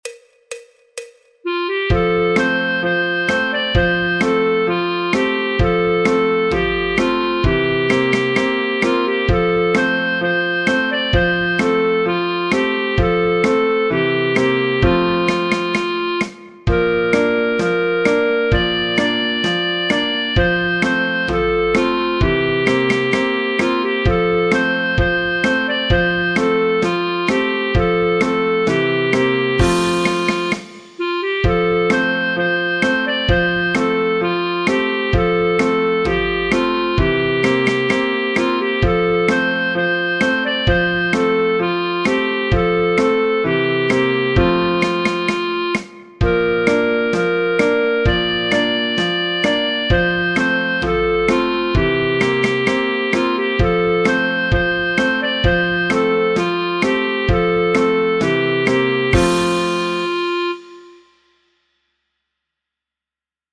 El MIDI tiene la base instrumental de acompañamiento.
Folk, Popular/Tradicional